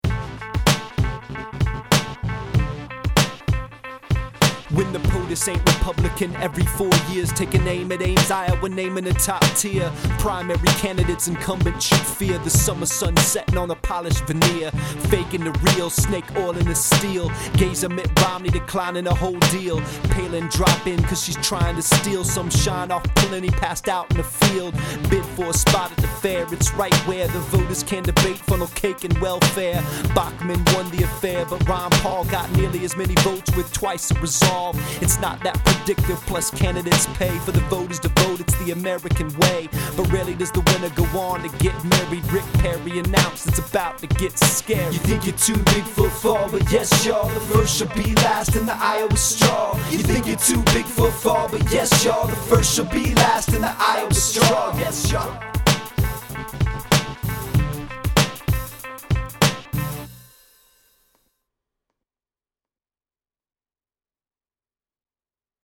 Thanks for supporting rap news.